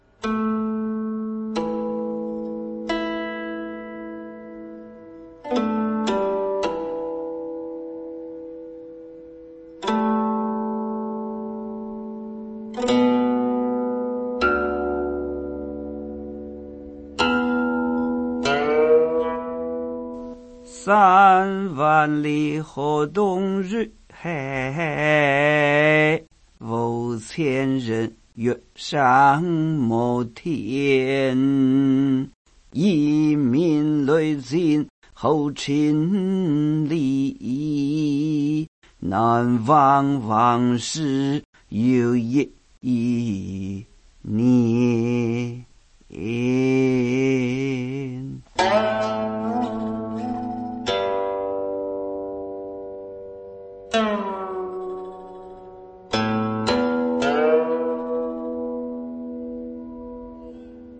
吟哦